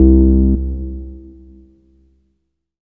Deep Bass.wav